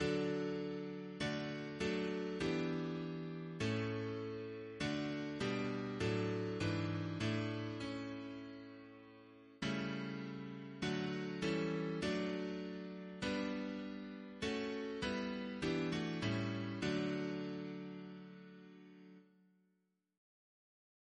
CCP: Chant sampler
Double chant in D Composer: Shirley Hill (1933-2014) Note: after Nicholson Reference psalters: ACP: 86